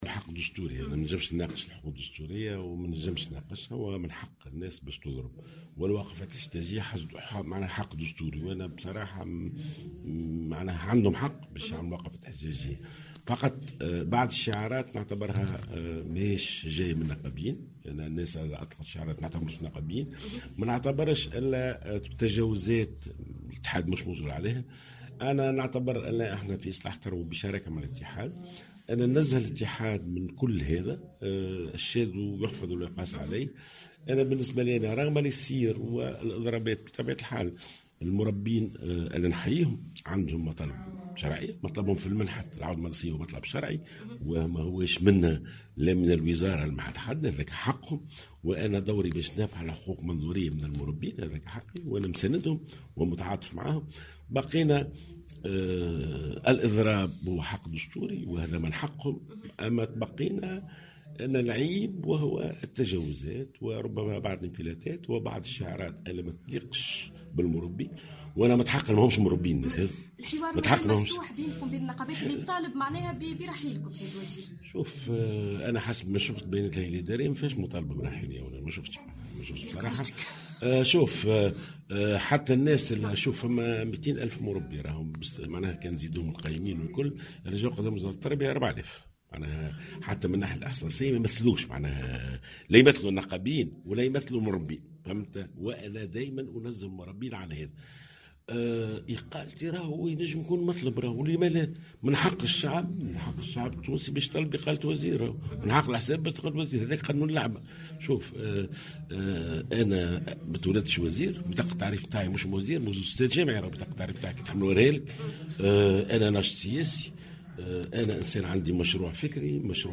ونفى جلول في تصريحات صحفية لـ "الجوهرة أف أم" على هامش اجتماع أشرف عليه، لإطارات حزب نداء تونس بالساحلين بولاية المنستير، أي تراجع عن الزمن المدرسي الذي تم ضبطه باستثناء تعديلات قد يتم ادخالها، مشيرا إلى ان العودة المدرسية العام الحالي كانت يوم 15 سبتمبر بسبب ضغوطات إلا أنه في العام الدراسي المقبل ستكون العودة المدرسية في الأول من شهر سبتمبر مما سيتيح ارجاع كل من عطلة الشتاء وعطلة الربيع إلى نسقها العادي، بحسب تعبيره.